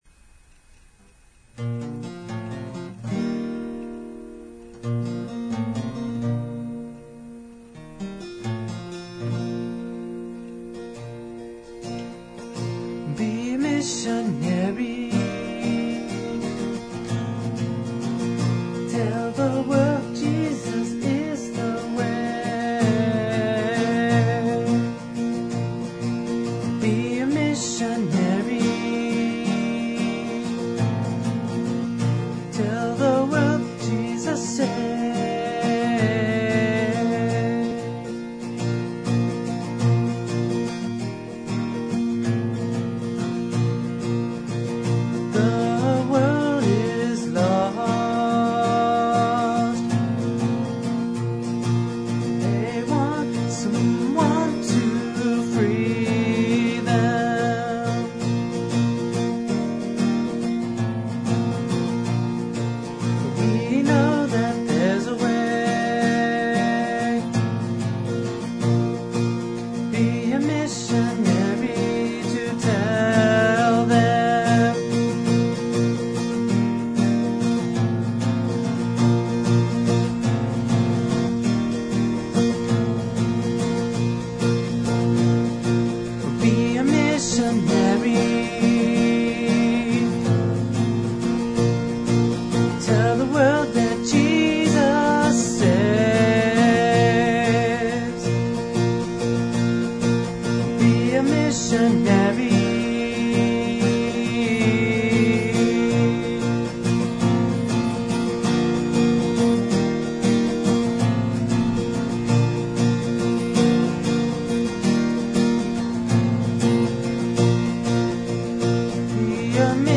The songs on the site are rough cuts.